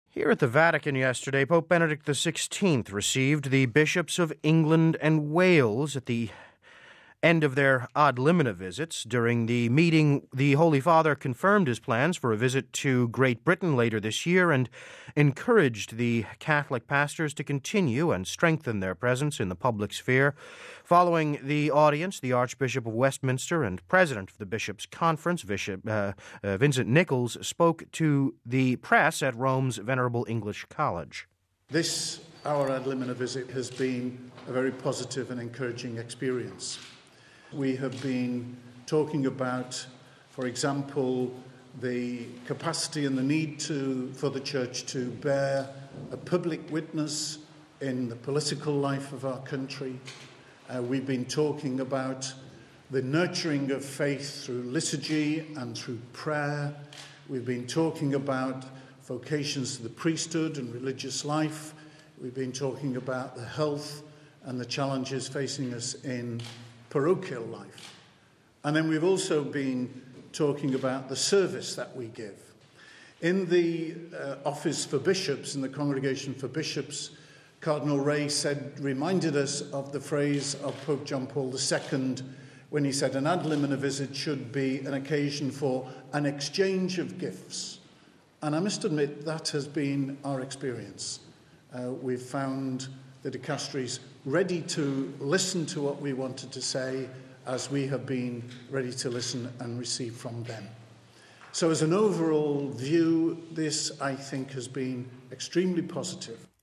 During the meeting the Holy Father confirmed his plans for a visit to Great Britain later this year, and encouraged the Catholic pastors to continue and strengthen their presence in the public sphere. Following the audience, the Archbishop of Westminster and President of the Bishops’ Conference, Vincent Nichols spoke to the Press at Rome’s Venerable English College…